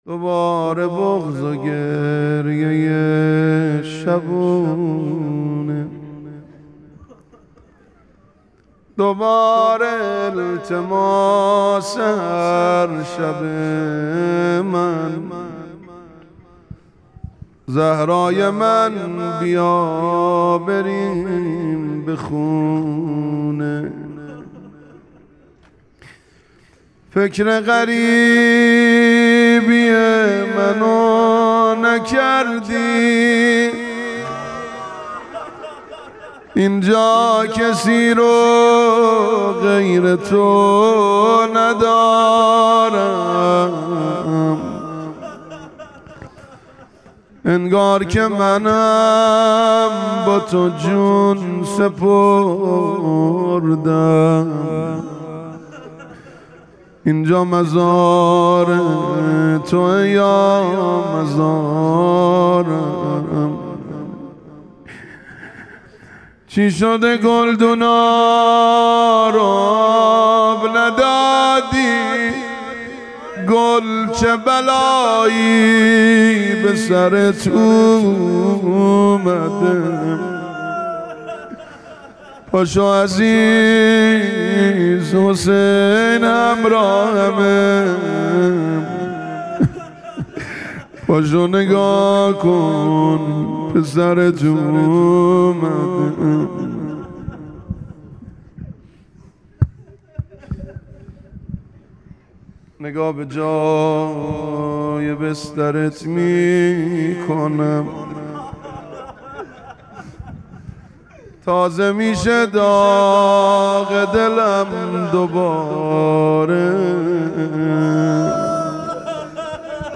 روضه فاطمیه